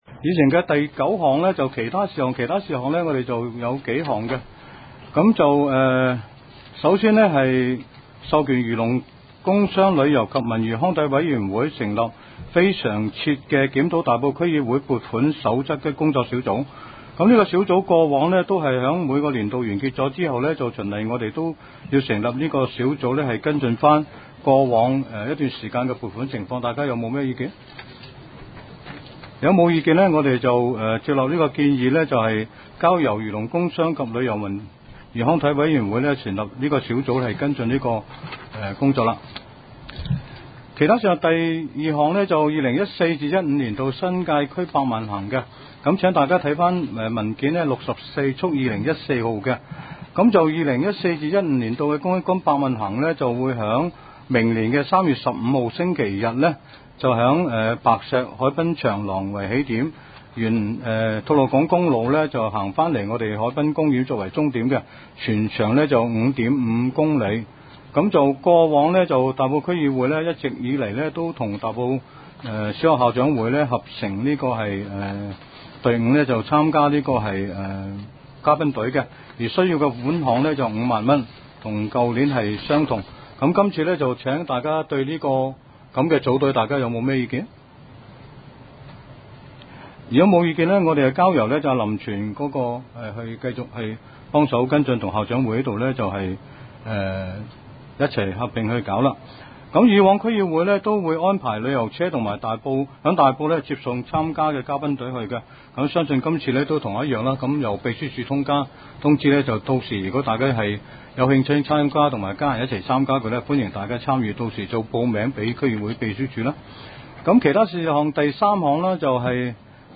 区议会大会的录音记录
大埔区议会秘书处会议室